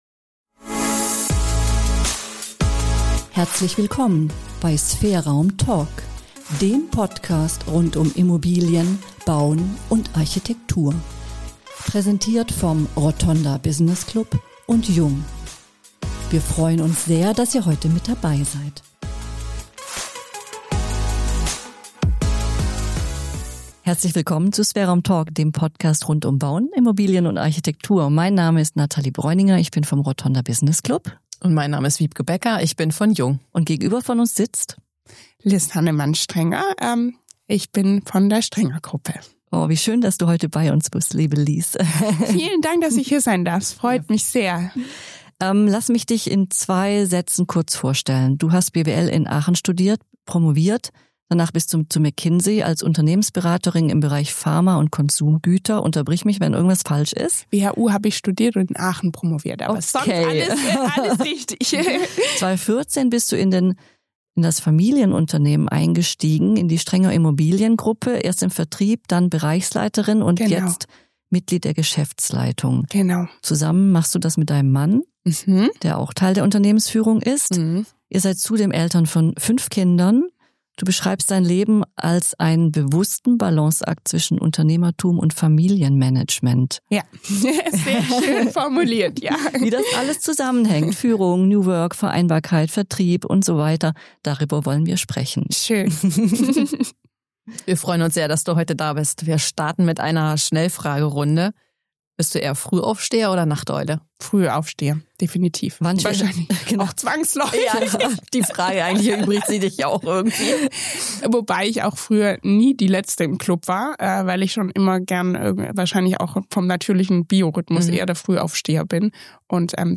Ein Gespräch über Haltung, Verantwortung und darüber, was Unternehmertum heute ausmacht.